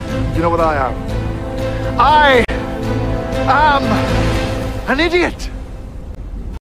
"I Am An Idiot!" Speech sound effects free download